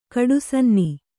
♪ kaḍusanni